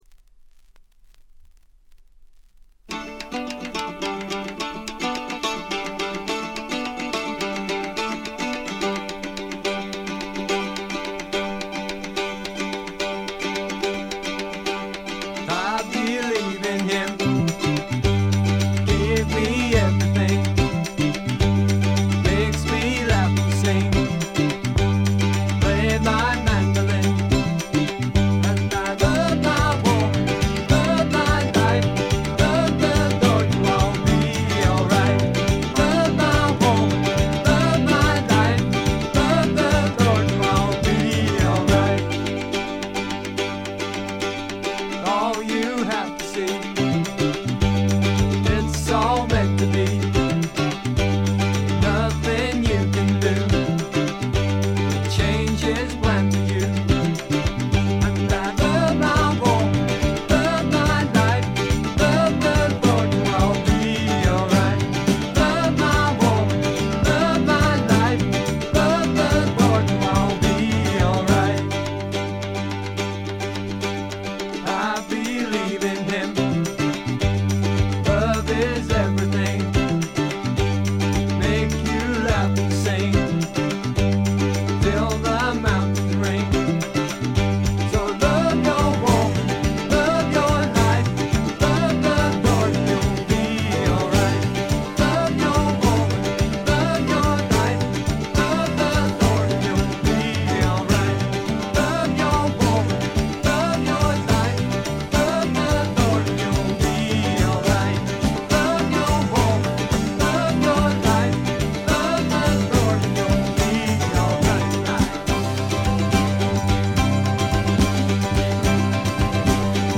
部分試聴ですが、ほとんどノイズ感無し。
特に変幻自在のスライドギターは絶品にして至高！！
試聴曲は現品からの取り込み音源です。